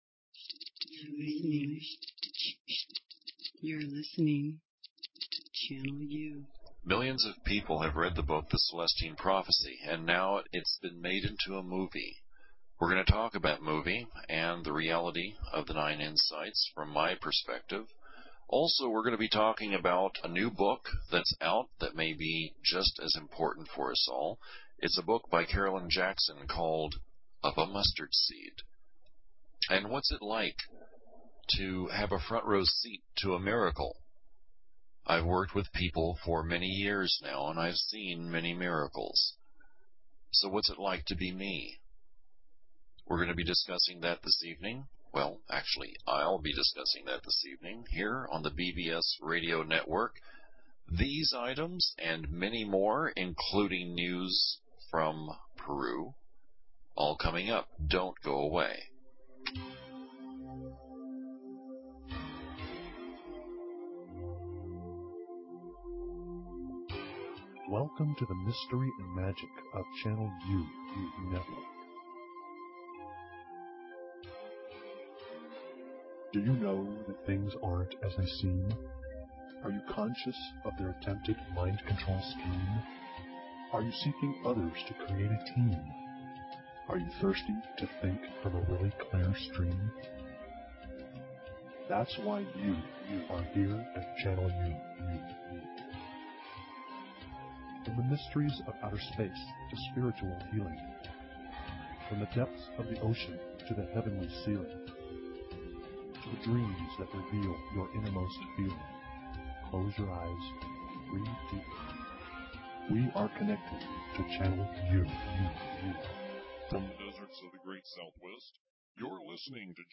Talk Show Episode, Audio Podcast, Channel_U and Courtesy of BBS Radio on , show guests , about , categorized as